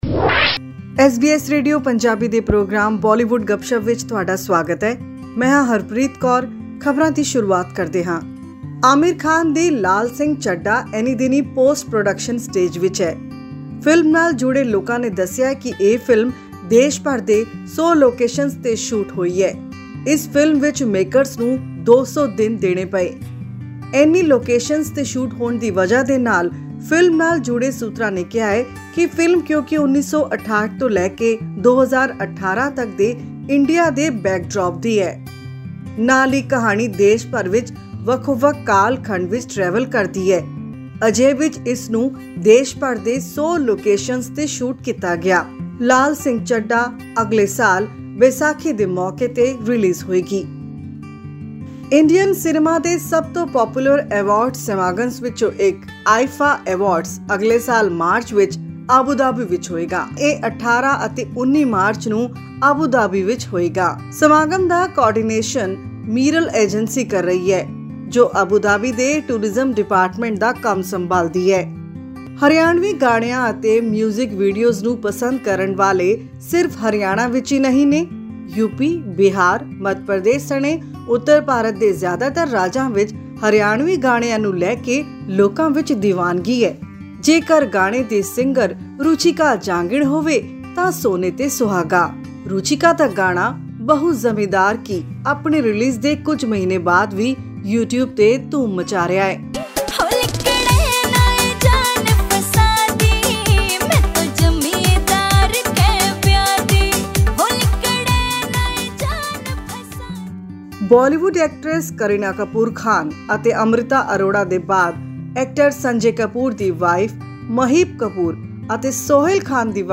The makers of the upcoming movie Lal Singh Chaddha have disclosed that it took over 200 days to complete the movie which is an Indian story from the backdrop of 1968 to 2018. Listen to this and much more right from Bollywood in our weekly news bulletin Bollywood Gupshup.